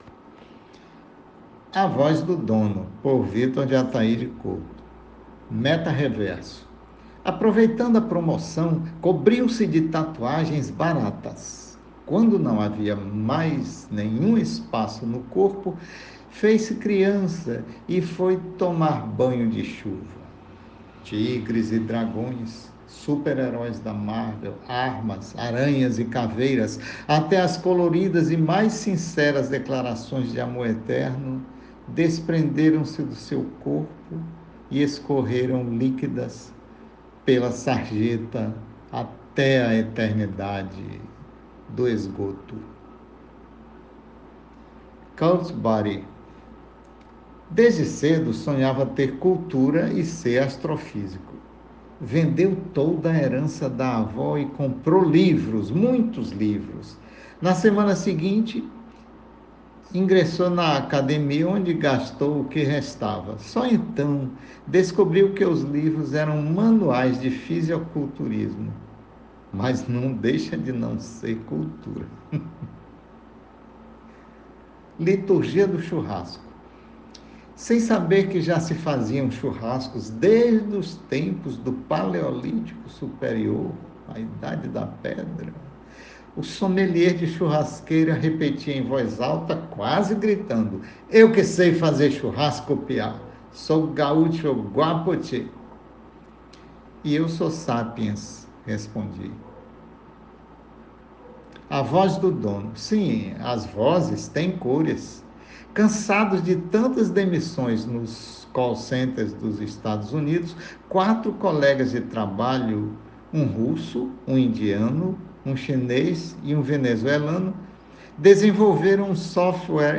Escute o texto com a narração do próprio autor: